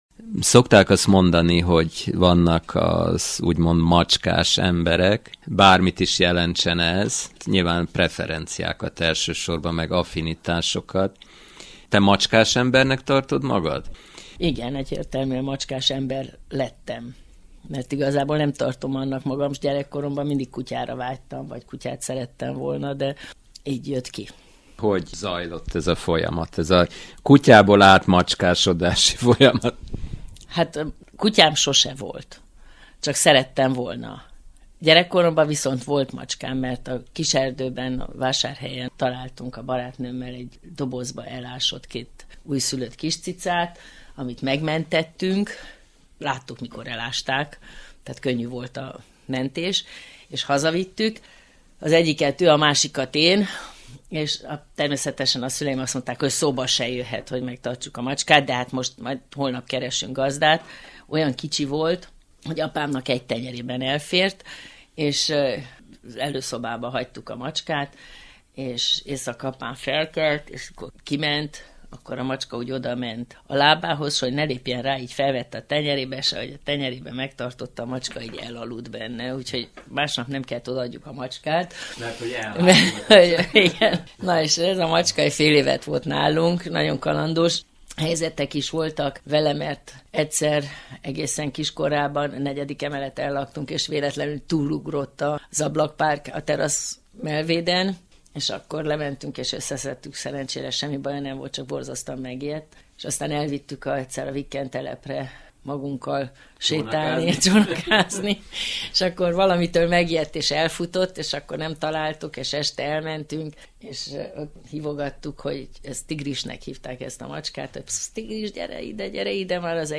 A teljes beszélgetés hangfelvétele itt vagyon: Fotók